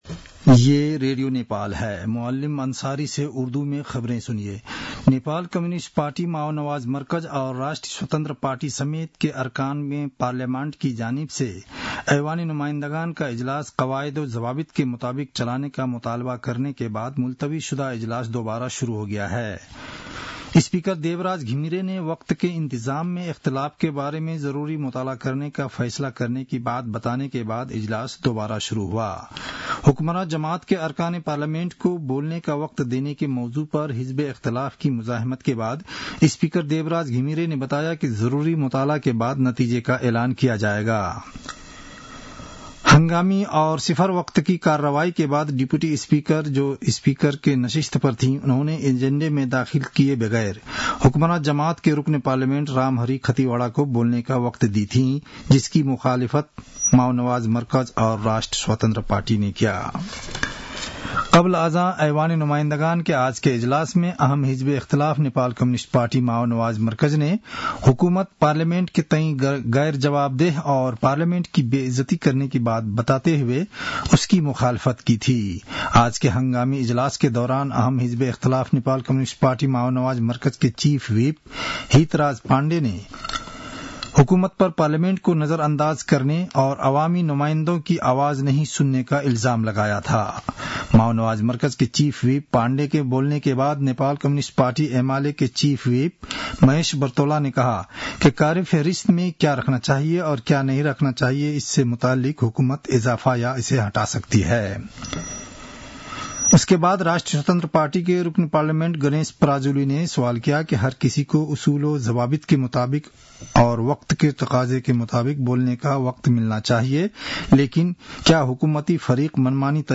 उर्दु भाषामा समाचार : ३० माघ , २०८१